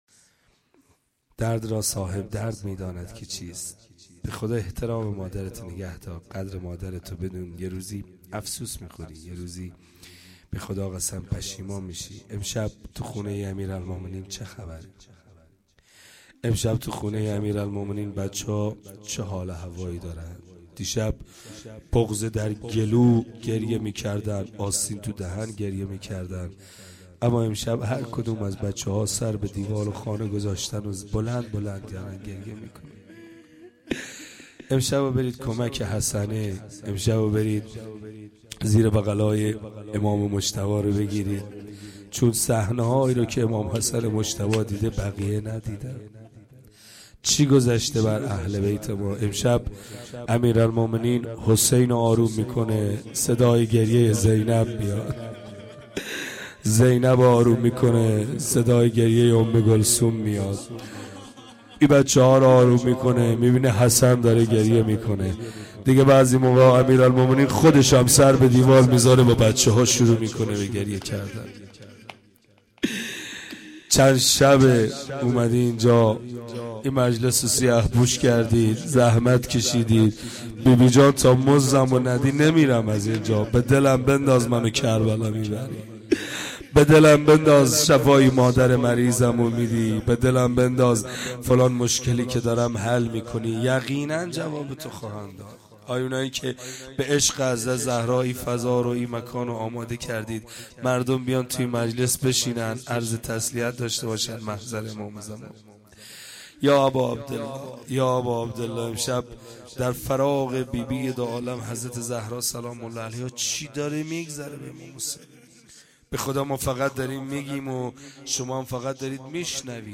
roze.mp3